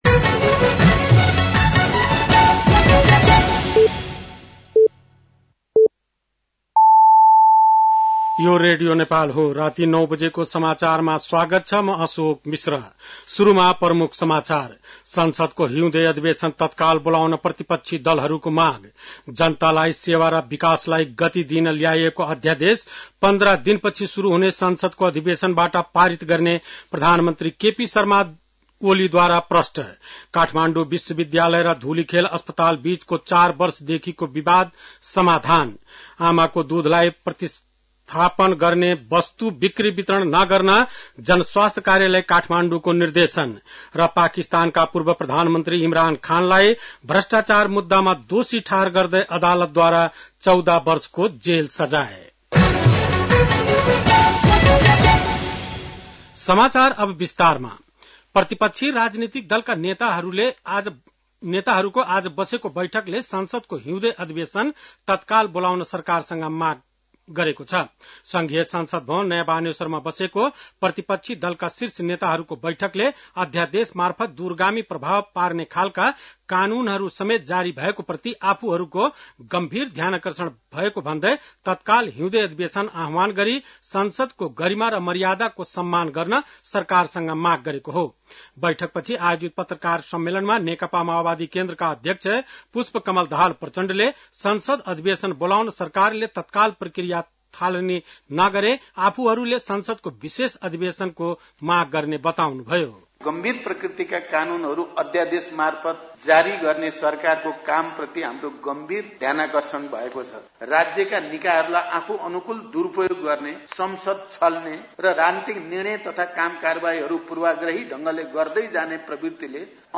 बेलुकी ९ बजेको नेपाली समाचार : ५ माघ , २०८१
9-PM-Nepali-News-10-4.mp3